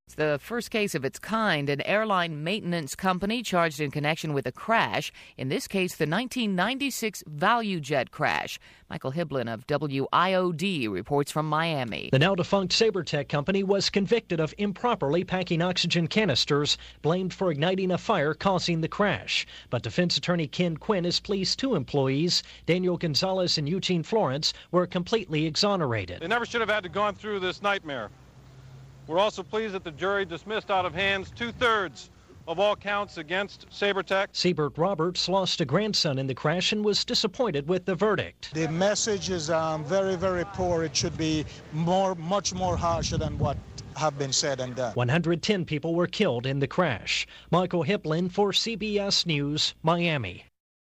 I interviewed Hays twice over several decades about his work to preserve the Rock Island bridge over the Arkansas River, which at one point was slated to be torn down. Below is audio and a transcript of our final interview, which also delved into his experiences working as a Missouri Pacific fireman and brakeman while in college.